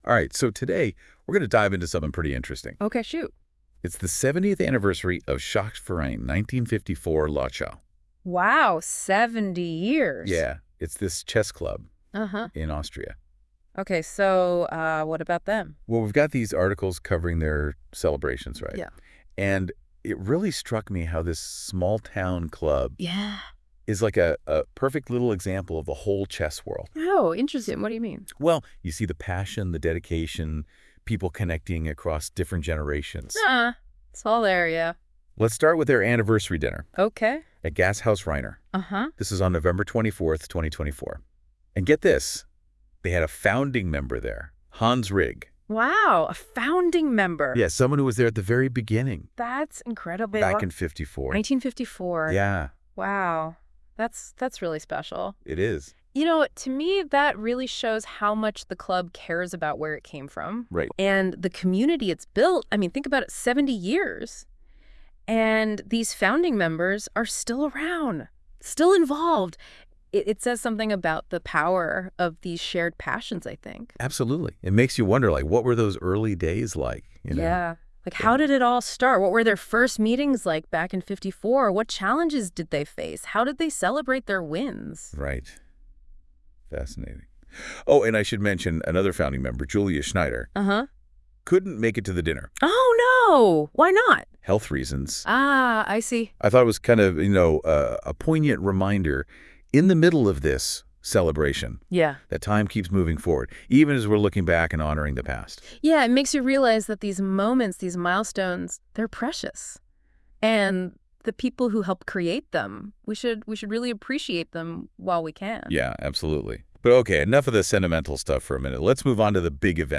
Eine Zusammenfassung kann man in einem englischen Podcast anhören, der mit NotebookLM und KI erstellt wurde.